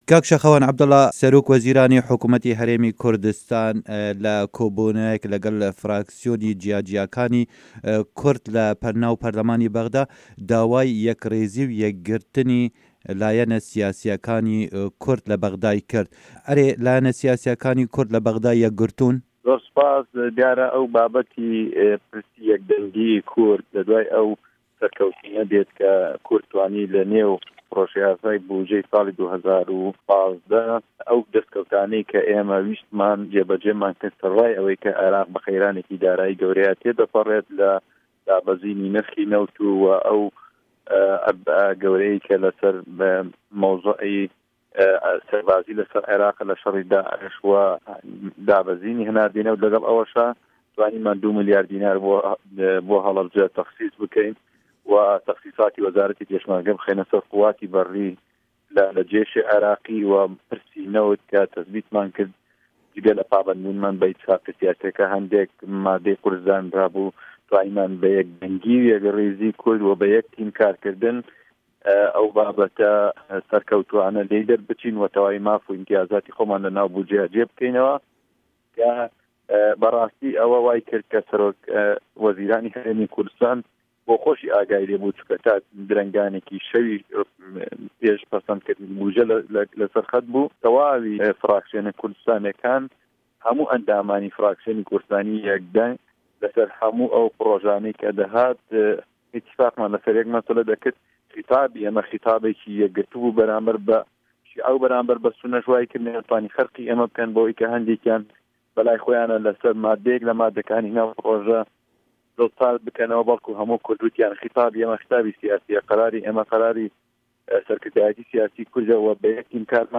hevpeyvin digel Şaxewan Edbulla